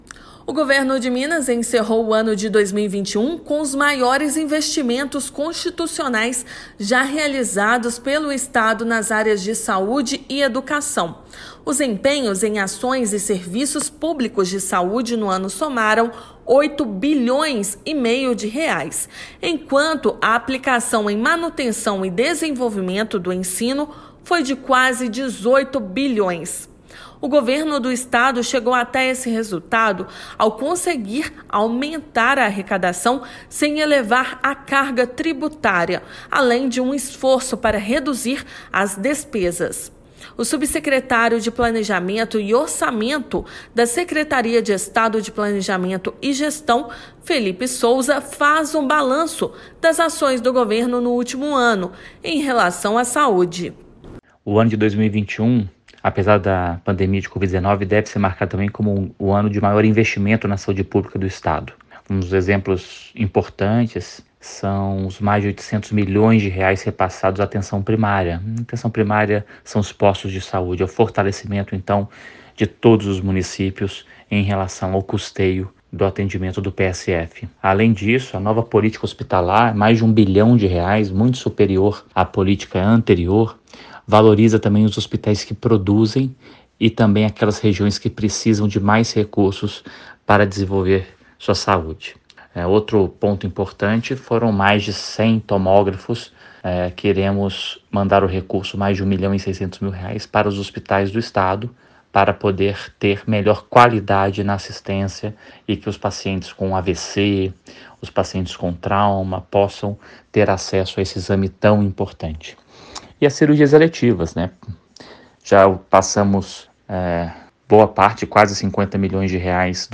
[RÁDIO] Governo de Minas investiu, em 2021, os maiores valores já aplicados pelo Estado em Saúde e Educação
Subsecretário de Planejamento e Orçamento da Secretaria de Estado de Planejamento e Gestão exalta esforço do governo para chegar ao resultado. Ouça matéria de rádio.